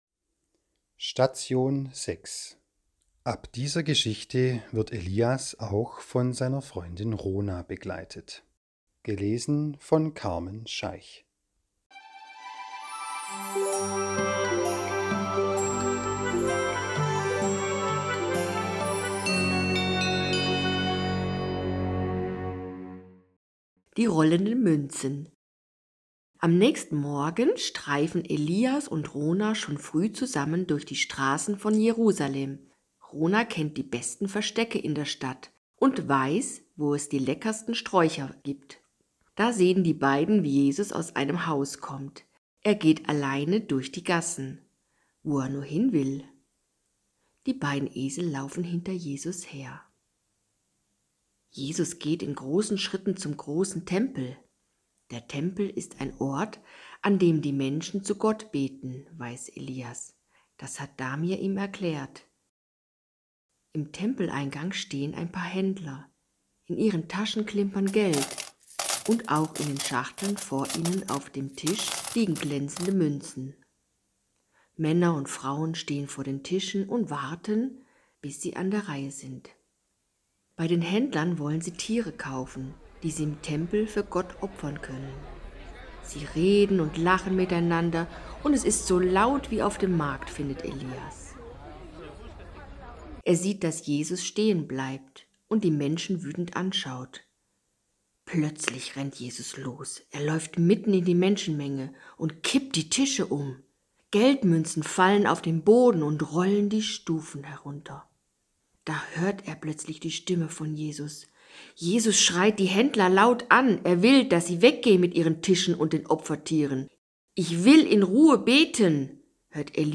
Auch 2025 könnt ihr mit dem Esel Elias wieder die Oster-Hör-Geschichte über Jesus und seine Freunde erleben.